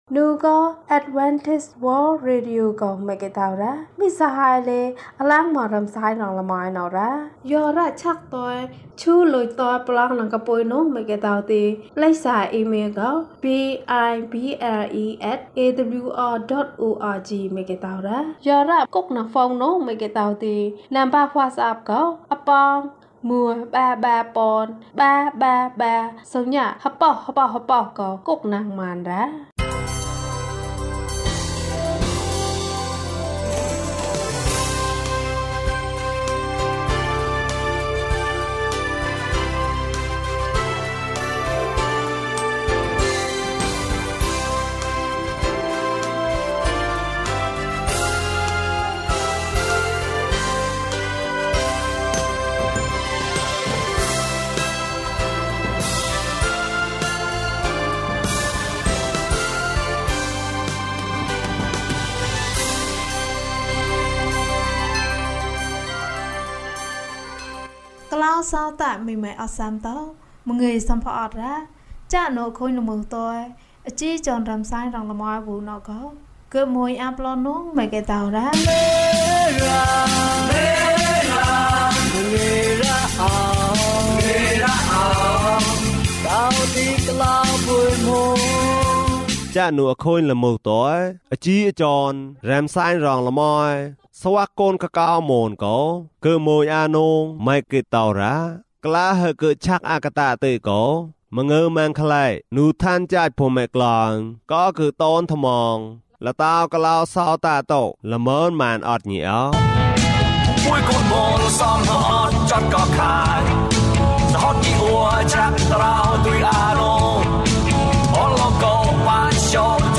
ခရစ်တော်ဆီသို့ ခြေလှမ်း။၅၅ ကျန်းမာခြင်းအကြောင်းအရာ။ ဓမ္မသီချင်း။ တရားဒေသနာ။